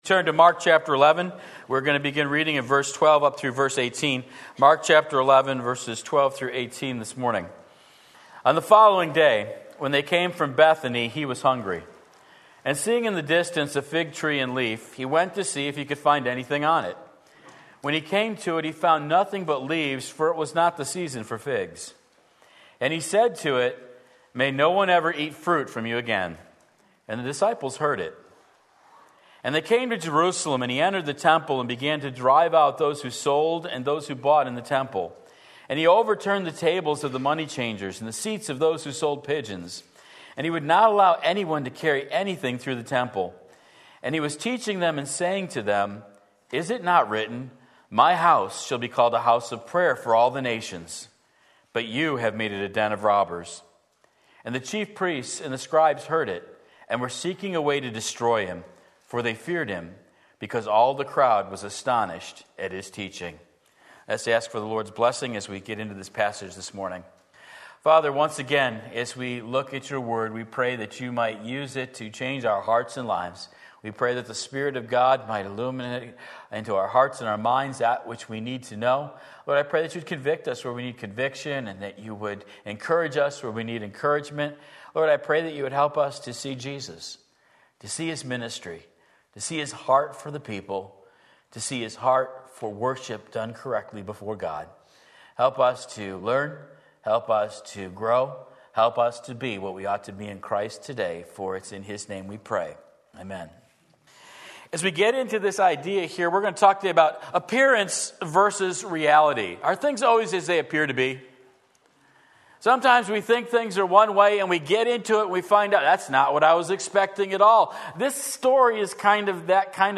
Sermon Link
Appearance Versus Reality Mark 11:12-18 Sunday Morning Service